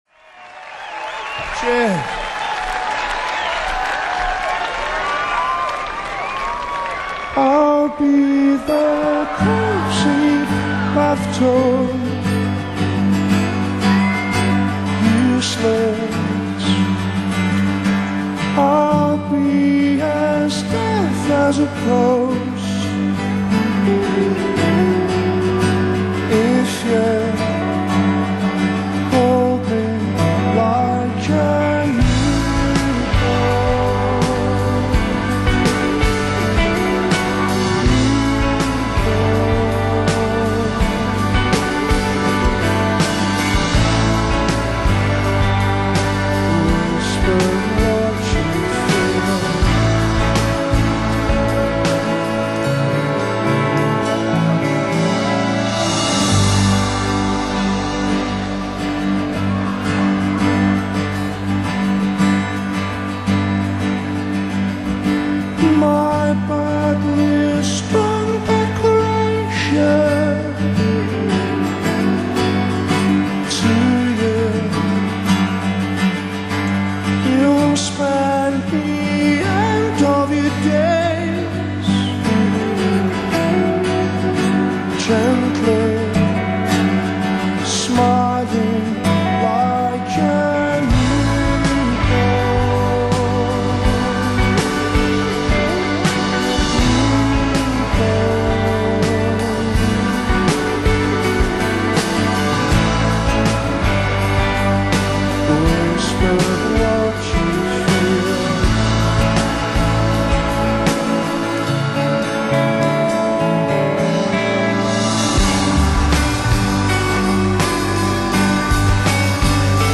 recorded live on June 28